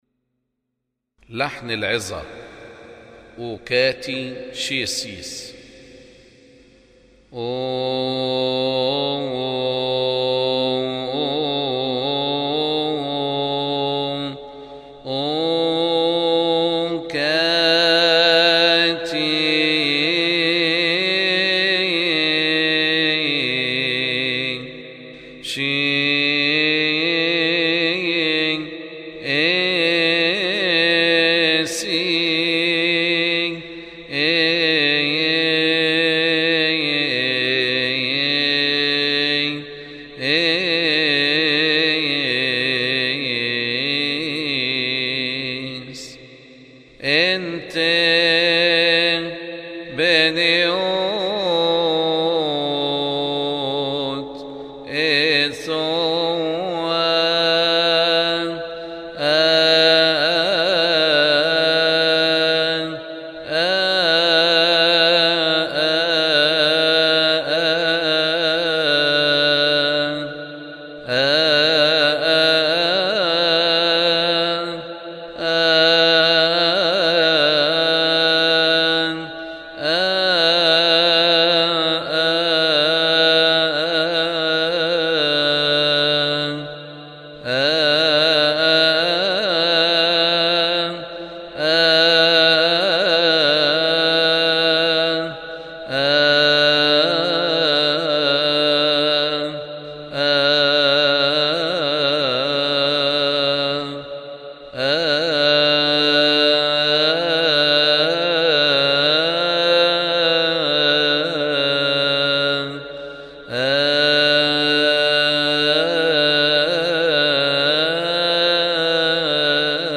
لحن_مقدمه_العظه_اوكاتي.mp3